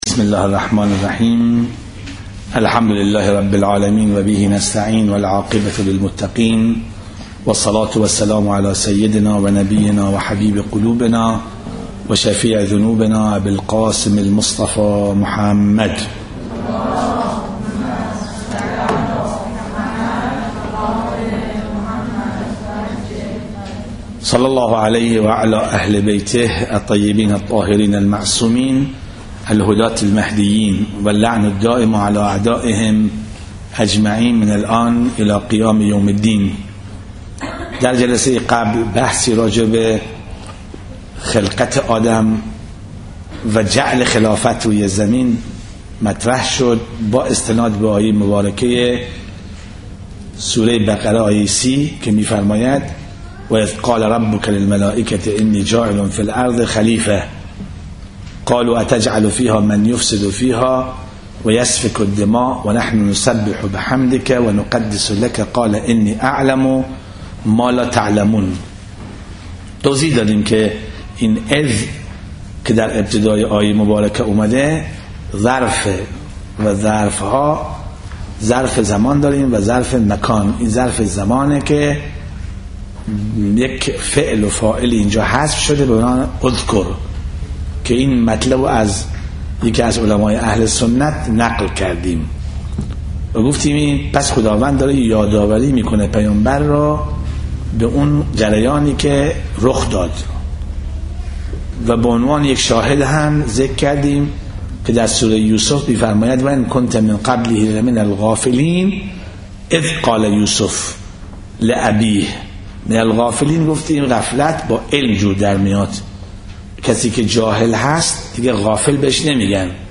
سخنرانی مهدوی